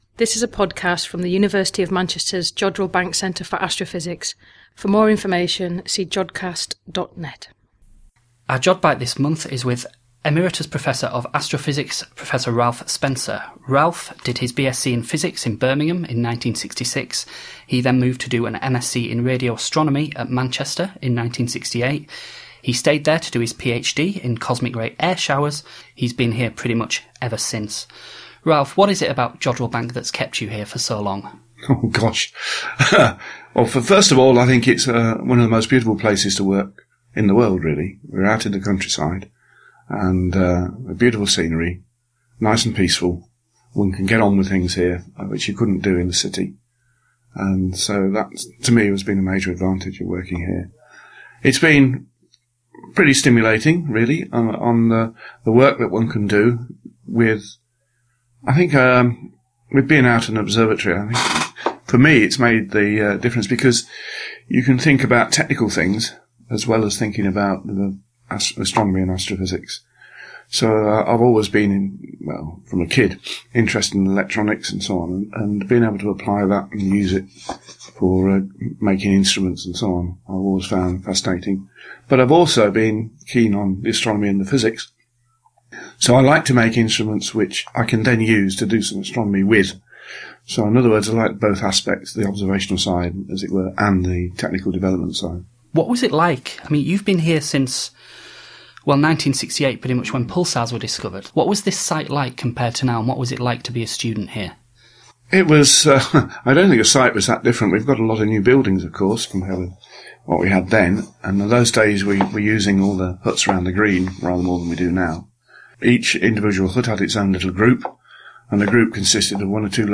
We left the comfort of our recording studio, packed up all our recording equipment and jumped on a bus to Jodrell Bank Observatory